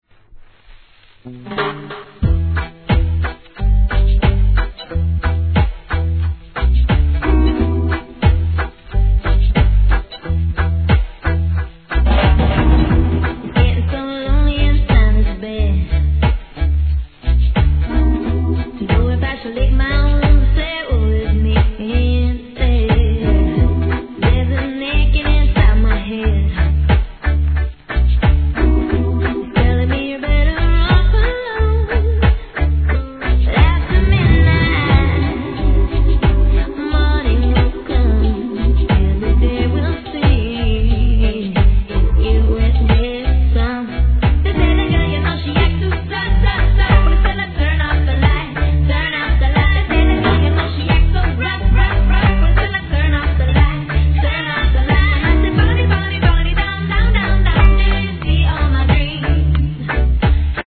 関連カテゴリ REGGAE